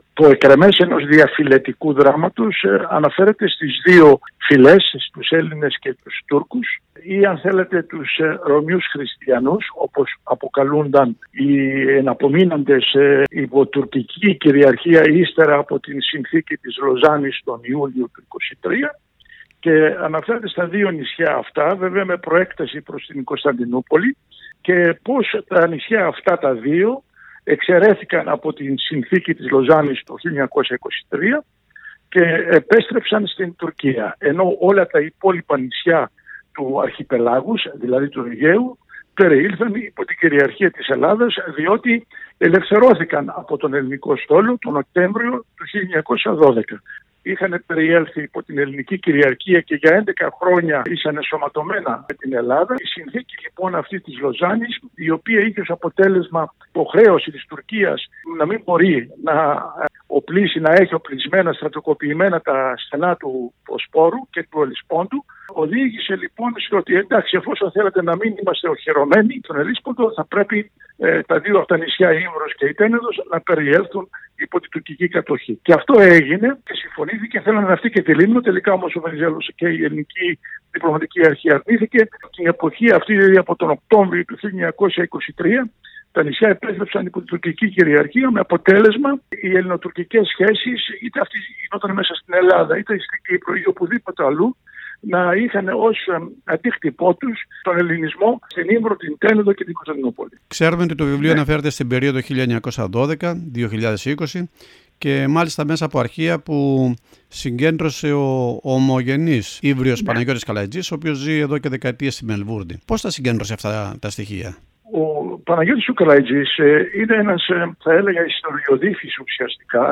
συνέντευξη στο SBS Greek